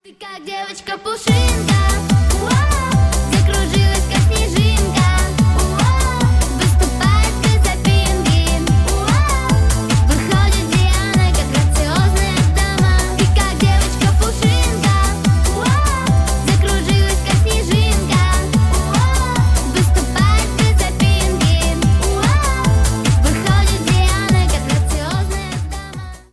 Поп Музыка
пародия